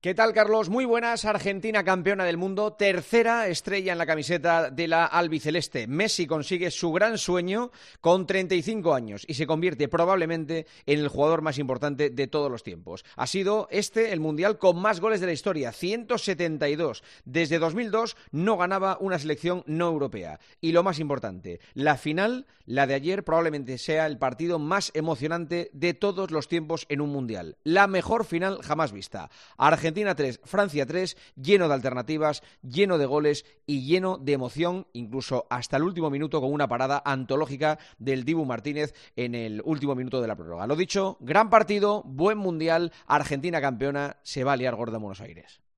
El comentario de Juanma Castaño
El director de 'El Partidazo de COPE' analiza la actualidad deportiva en 'Herrera en COPE'